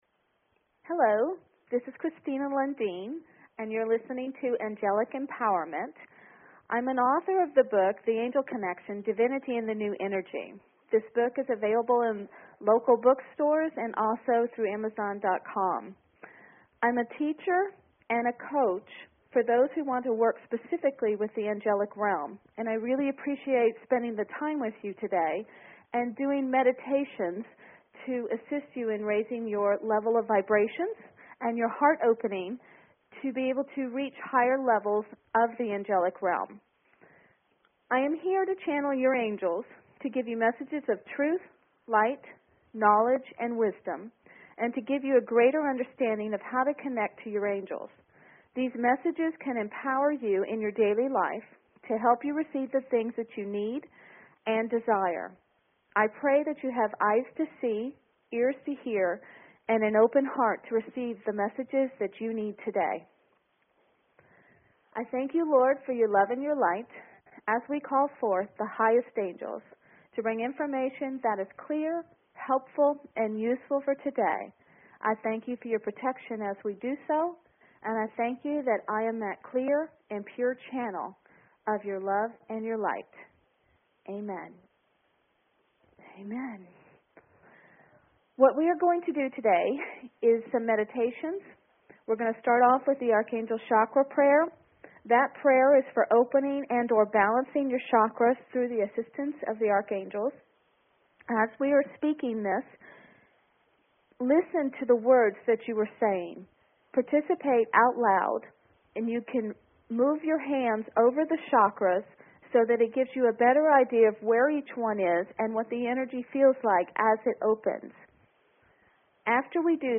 Talk Show Episode, Audio Podcast, Angelic_Empowerment and Courtesy of BBS Radio on , show guests , about , categorized as
Blessings abound when dealing with the Angelic realm. There will be Angel meditations, Angel teachings, Angel channelings… whatever the Angels wish to share to all.